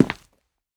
Step7.ogg